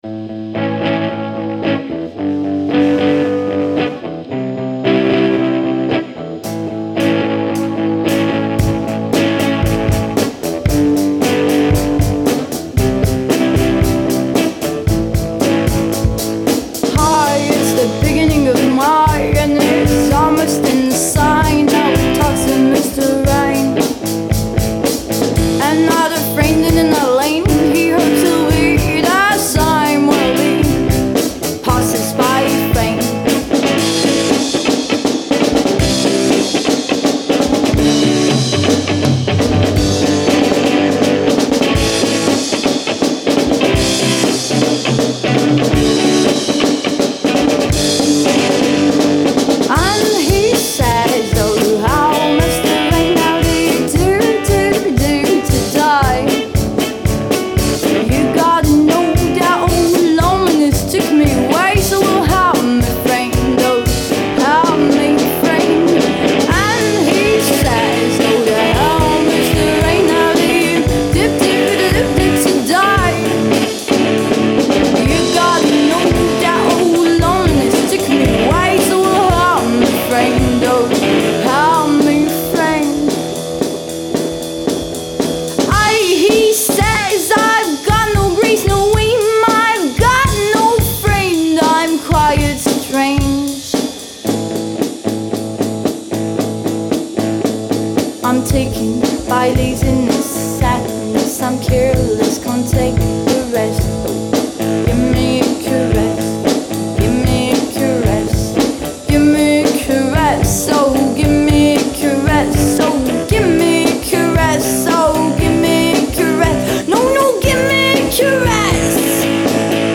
A l'indétrônable Jean Bart et aux voix de velours arrogantes et cyniques.